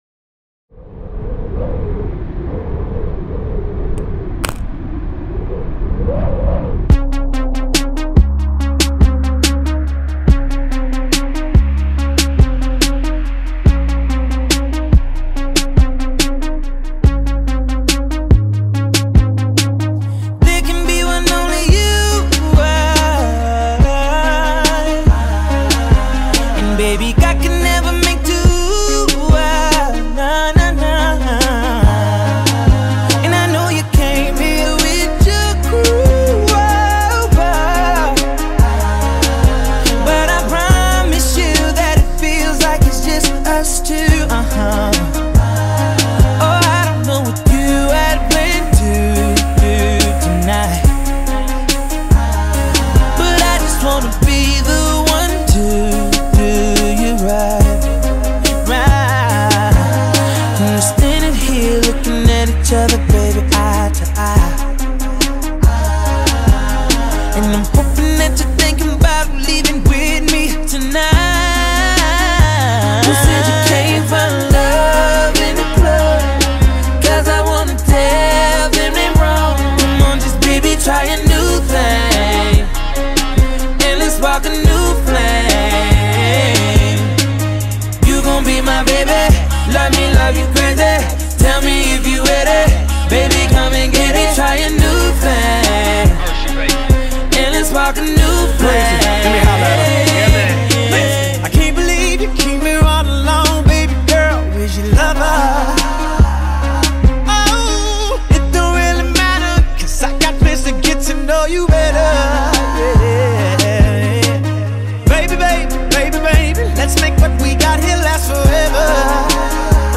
a powerful American Hip Hop and R&B singer